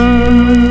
Index of /90_sSampleCDs/USB Soundscan vol.02 - Underground Hip Hop [AKAI] 1CD/Partition D/06-MISC
ORGAN 1   -L.wav